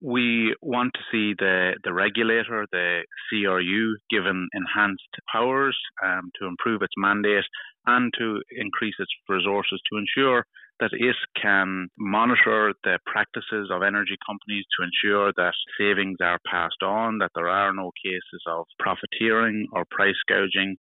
Sinn Féin’s Environment Spokesperson, Darren O’Rourke says the price of electricity in Ireland is now the highest in Europe.